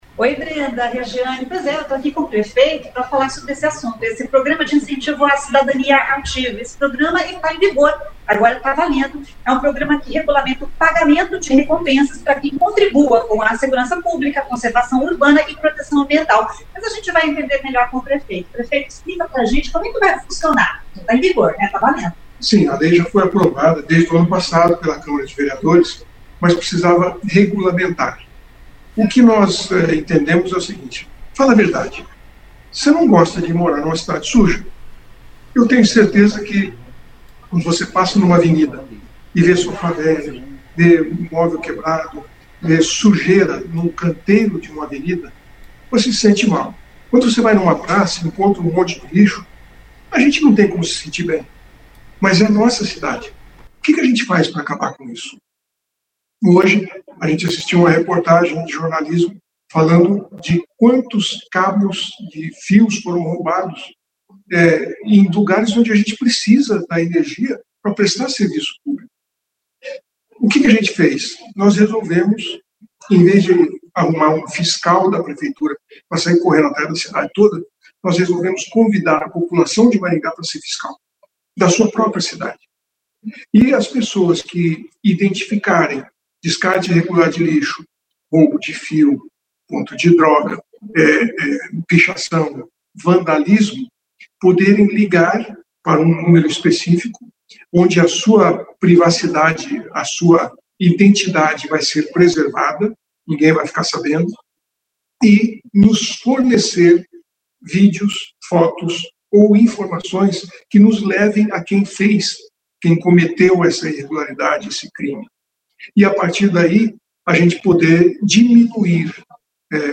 O prefeito Silvio Barros explica como vai funcionar o programa, que já está em vigor.